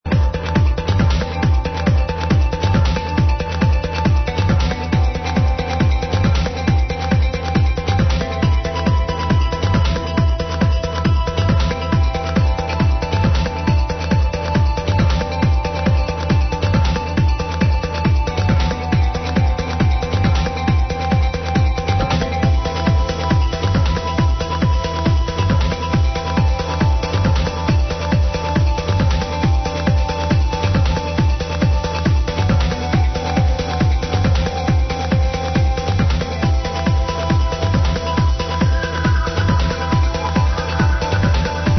Please Help To Id This Great Trance Track
This is a very great Trance Track !!!